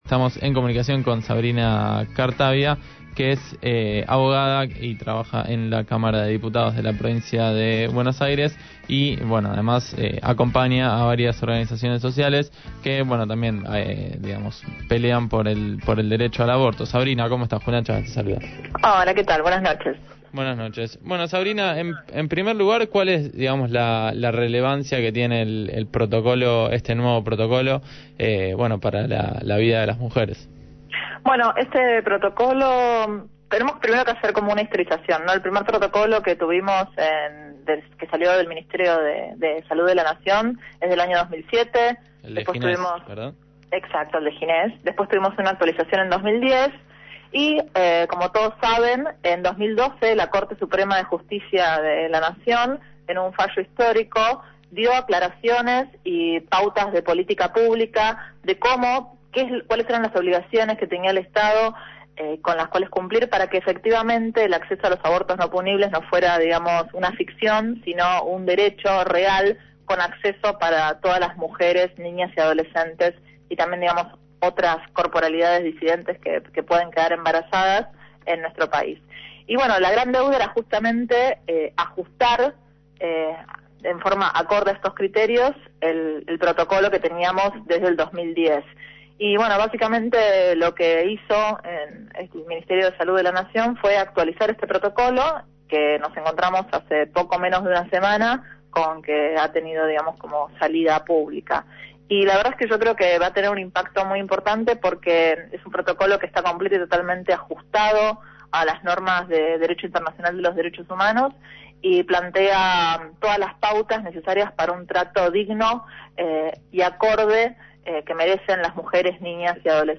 en diálogo con Sin Zonceras, analizó de qué se trata esta guía y sobre qué derechos y políticas públicas se inscribe este protocolo.